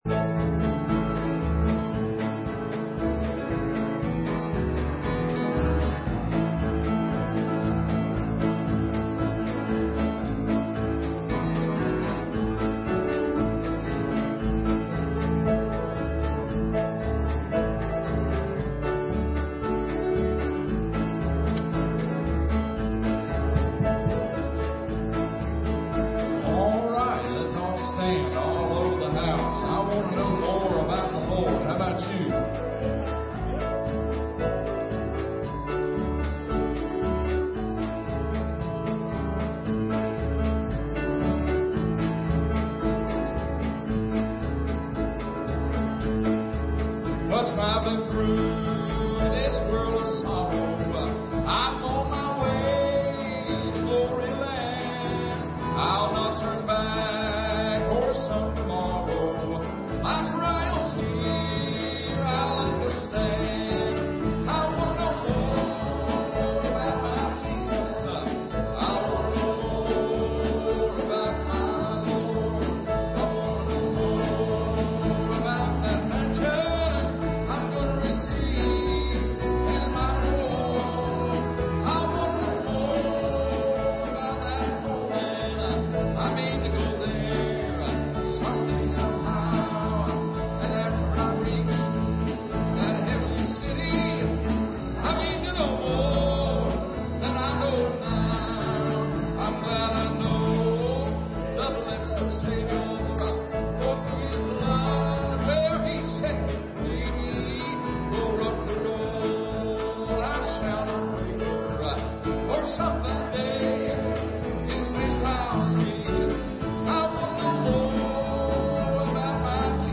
Wednesday Night Worship March 10, 2021
LIVE Worship Service at Maranatha Baptist Church - Dallas, NC
The Power of a Praying Church Acts 12 Sermon begins at 20:00 (turn volume down some during preaching due to the wrong audio setting)